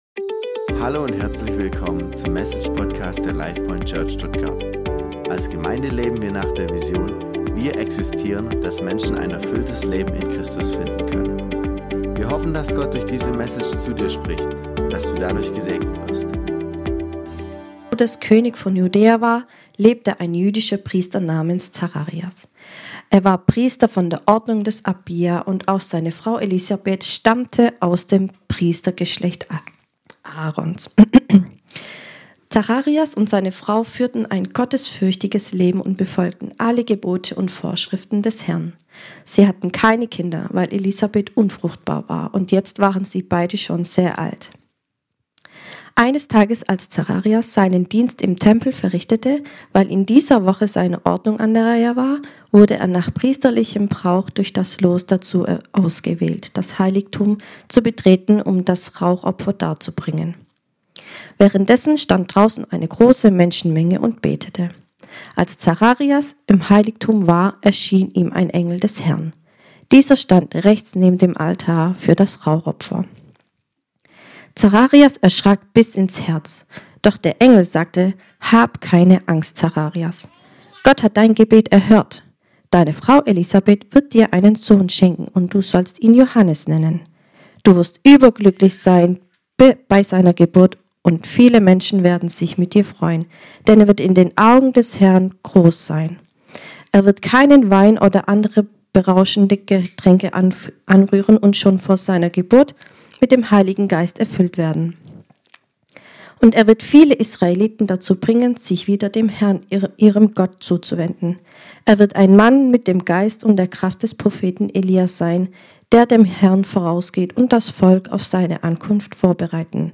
Die Predigt beleuchtet die Geschichte von Zacharias und Elisabeth, die durch Gottes Eingreifen auf wundersame Weise einen Sohn – Johannes – bekamen...